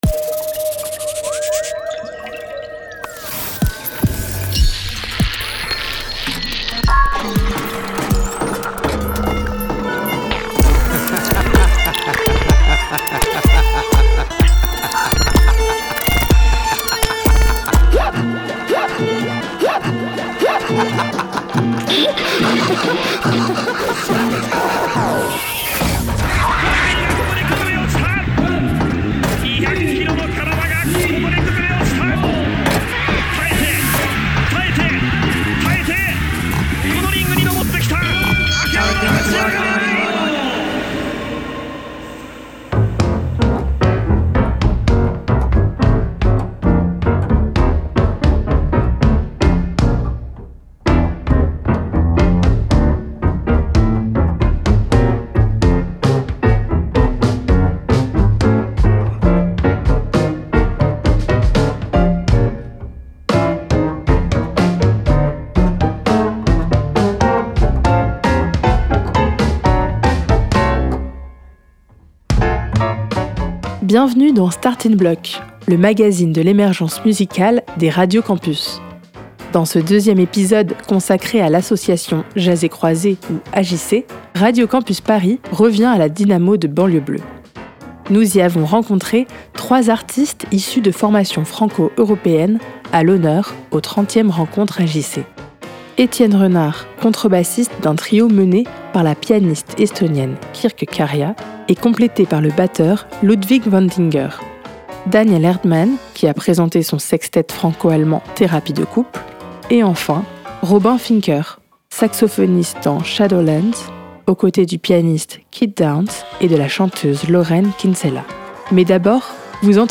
A l'occasion des 30e rencontres AJC qui se sont tenues à la Dynamo de Banlieues Bleues les 4 et 5 décembre, nous nous sommes entretenus avec trois artistes issus de formations franco-européennes.